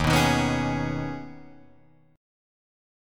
D#7sus4#5 chord